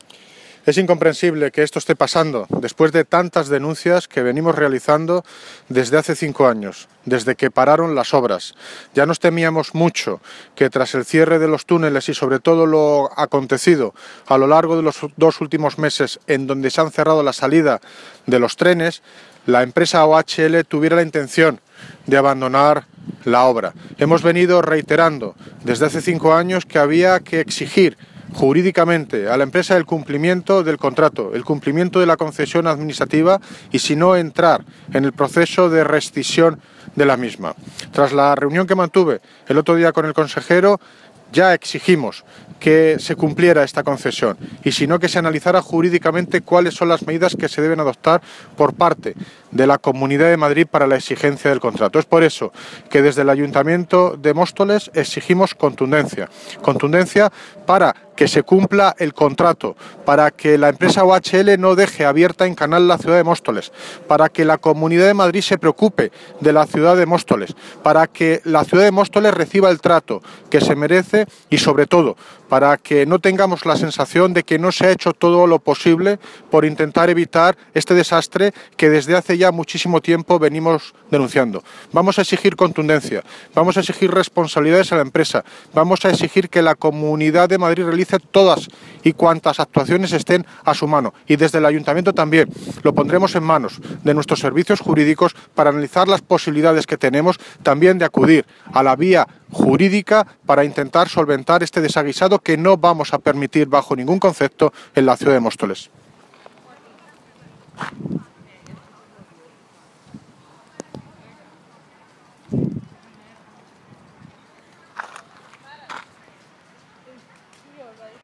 Audio de David Lucas, alcalde de Móstoles, sobre OHL